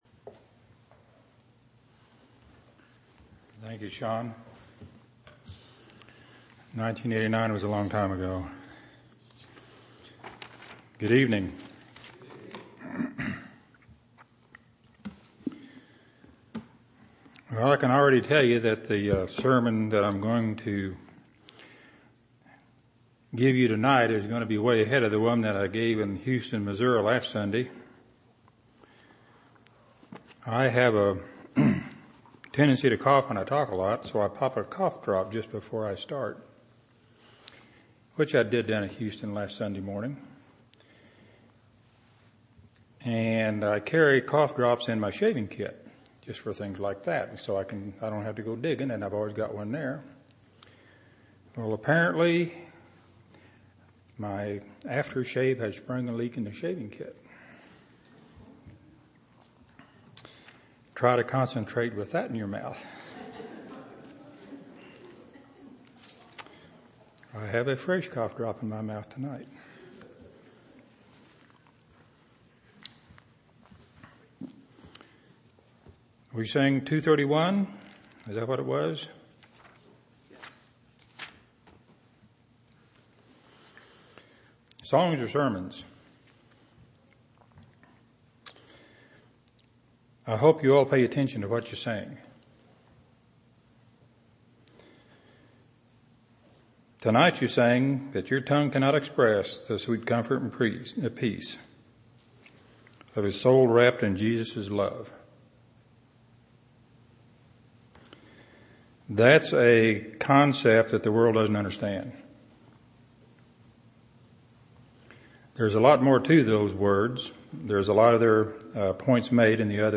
8/24/2008 Location: Temple Lot Local Event